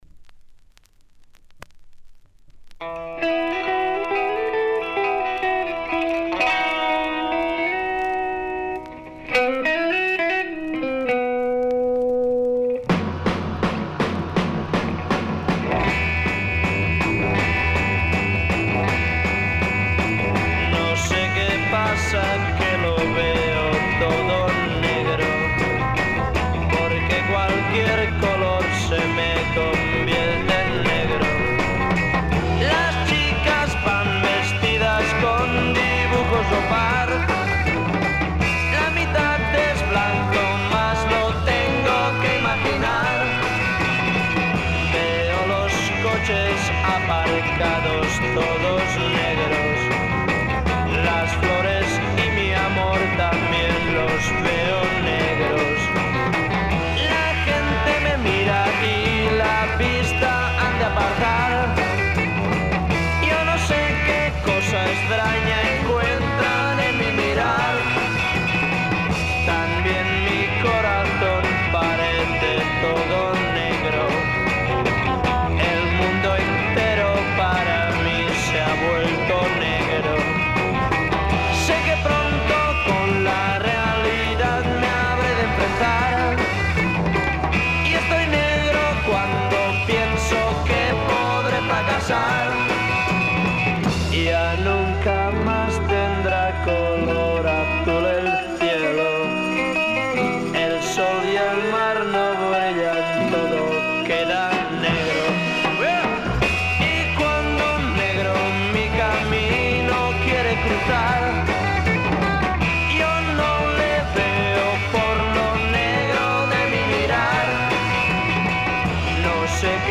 Published July 13, 2010 Garage/Rock Comments